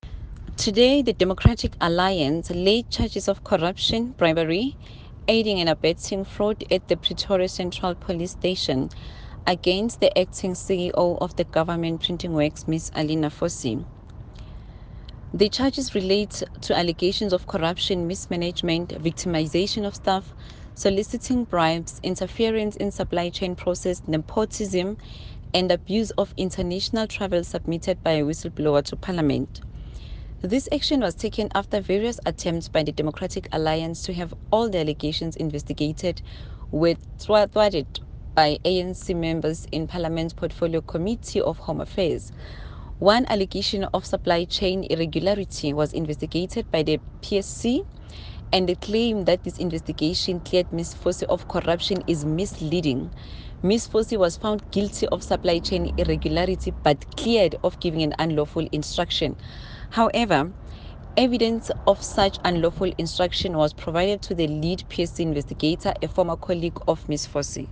English by Angel Khanyile, DA Minister of Home Affairs and  an